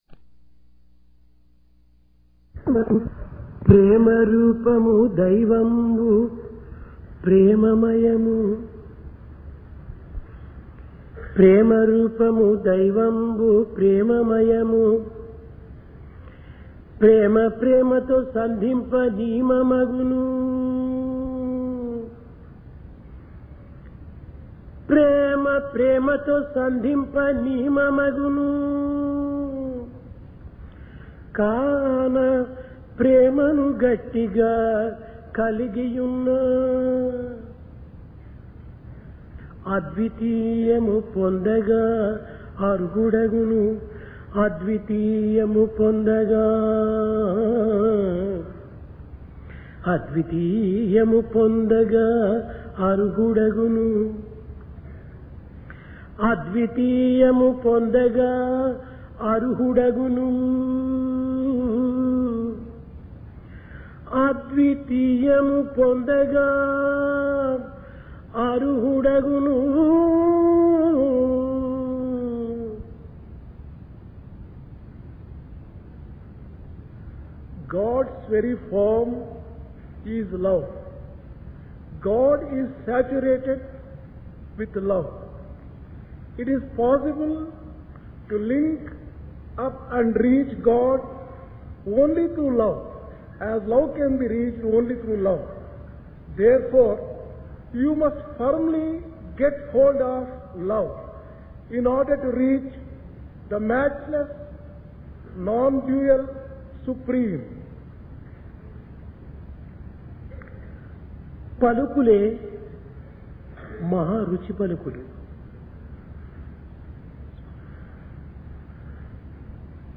Divine Discourse | Sri Sathya Sai Speaks